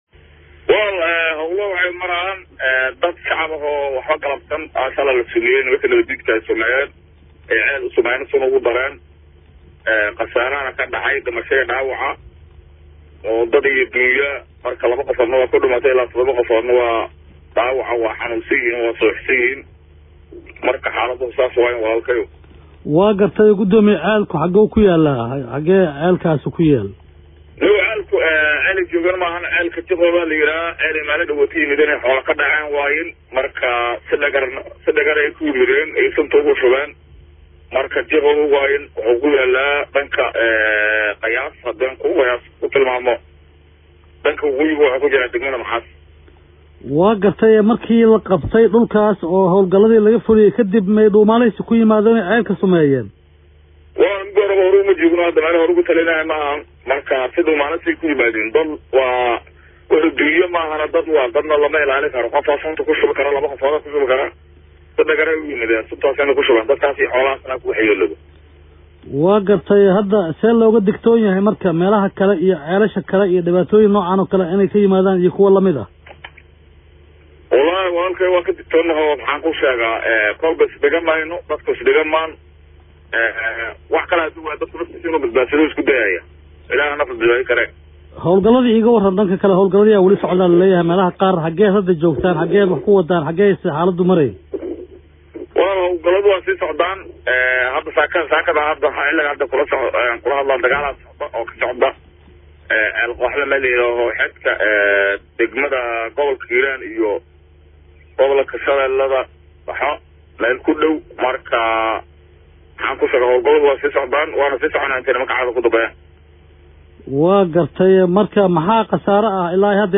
Alshabab oo sumeysay ceel-biyood ku yaala gobolka Hiiraan (Dhageyso wareysi)
Guddoomiyaha degmada Maxaas Muumin Maxamed oo la hadlay Radio Muqdisho ayaa sheegay in alshabaab ay sumeeyeen ceel biyoodka tuulada Jiqoow oo ka tirsan degmadaas, ayna waxyeelo ka soo gaartay dadka iyo duunyo.